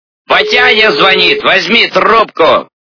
» Звуки » Люди фразы » Михаил Галустян - Батяня звонит! возьми трубку
При прослушивании Михаил Галустян - Батяня звонит! возьми трубку качество понижено и присутствуют гудки.